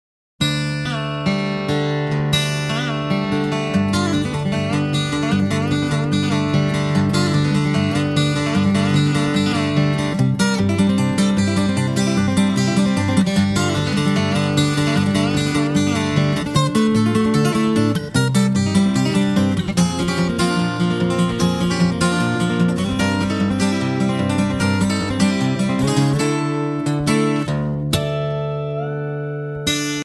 Voicing: Guitar Collection